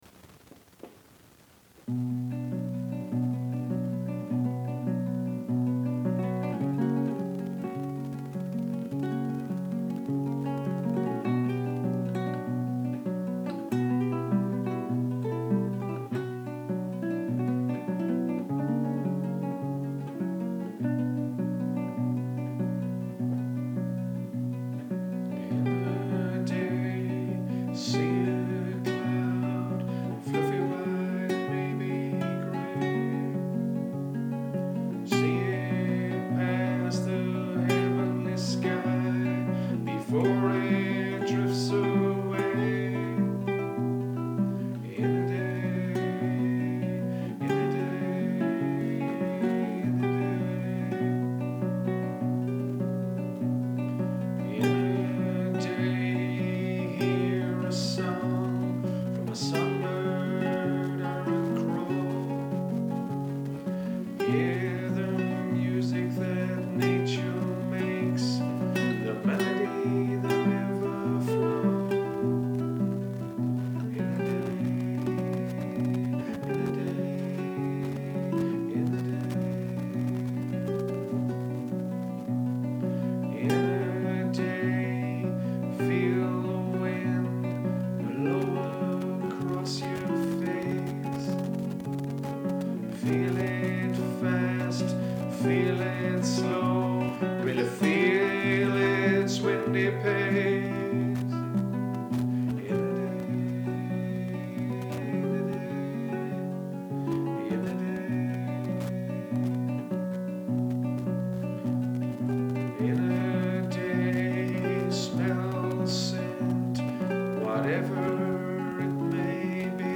You may also listen to the words put to music. I recorded it at home with basic equipment so there’s a bit of noise but perhaps this “noise” is perfect!)